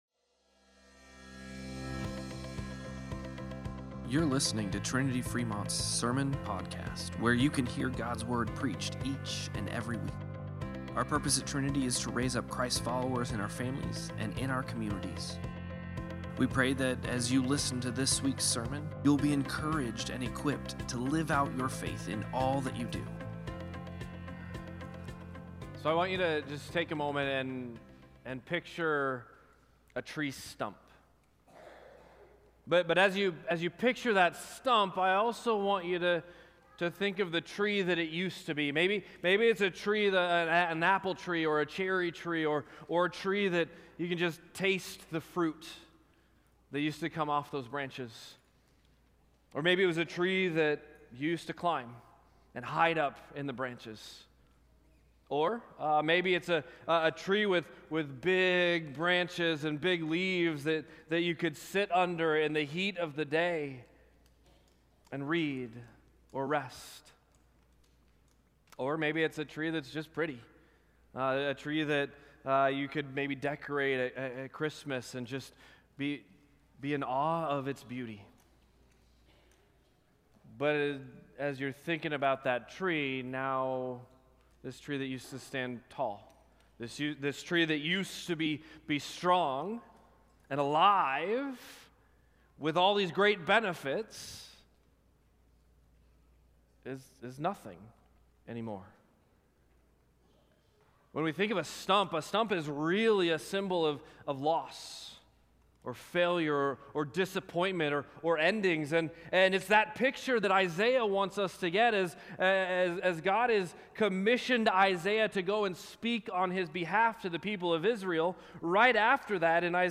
Sermon-Podcast-12-7.mp3